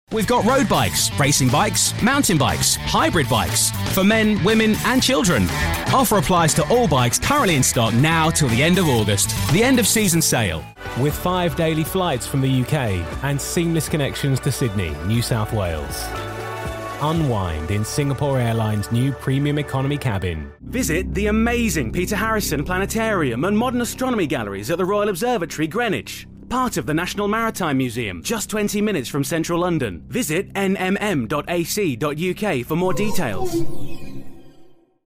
Inglés (Británico)
Comercial, Natural, Amable, Cálida, Empresarial
Comercial